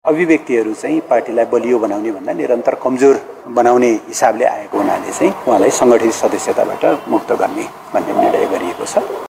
उक्त जिम्मेवारी पनि पूरा नगरेको भन्दै रावललाई सङ्गठित सदस्यबाट समेत मुक्त गरिएको एमाले उपमहासचिव प्रदीप ज्ञवालीले जानकारी दिए।